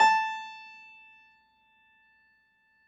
53h-pno17-A3.aif